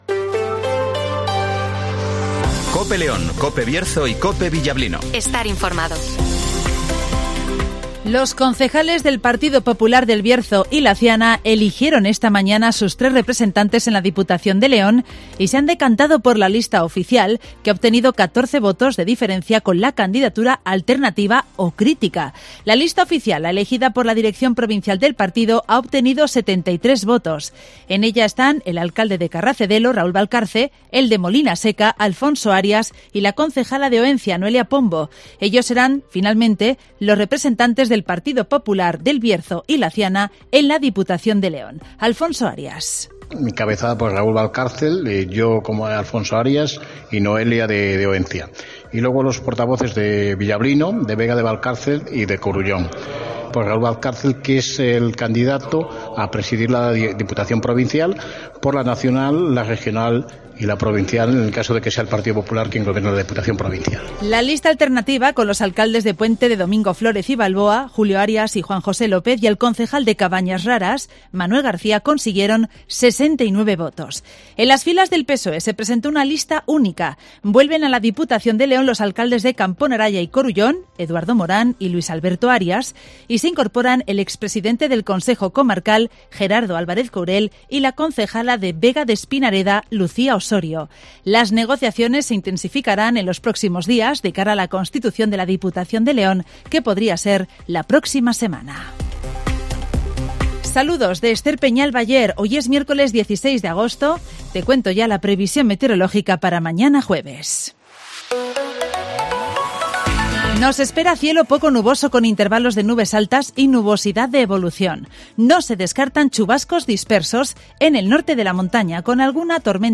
Boletines COPE